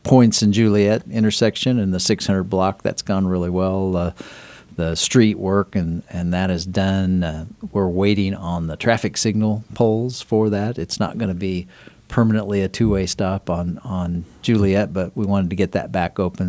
The goal of the project is to construct a new traffic signal along with new decorative crosswalks and sidewalks. Fehr says the road work is completed.